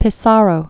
(pĭ-särō, pē-), Camille 1830-1903.